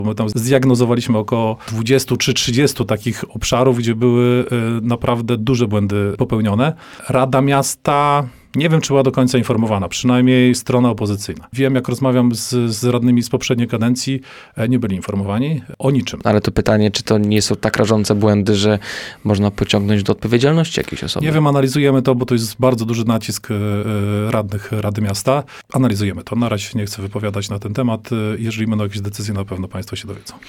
Burmistrz dodaje, że ta sytuacja będzie dokładnie przeanalizowana, bo takie błędy projektowe nie powinny mieć miejsca.